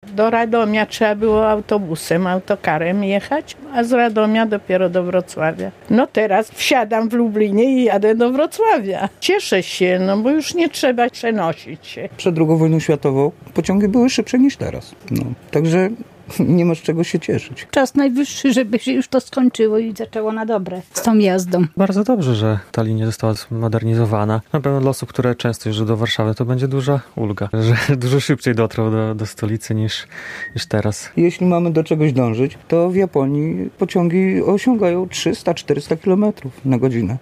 pasazerowie.mp3